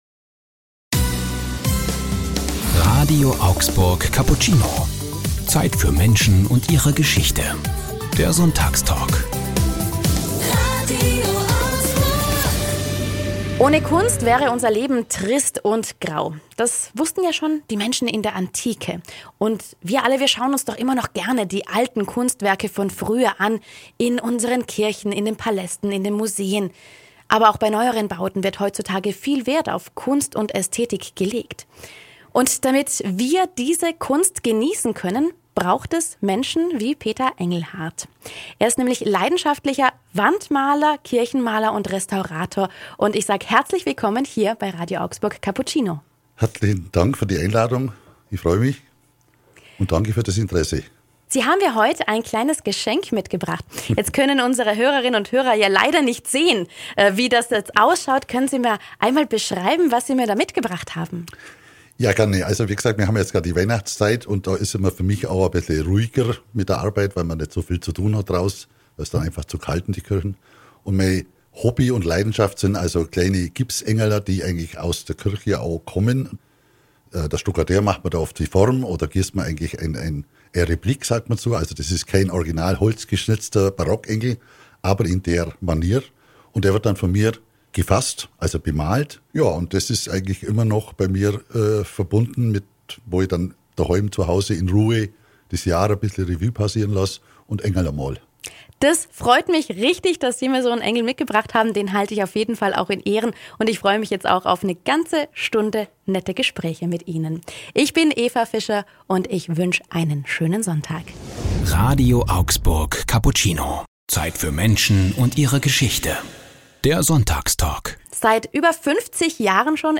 Sonntagstalk ~ RADIO AUGSBURG Cappuccino Podcast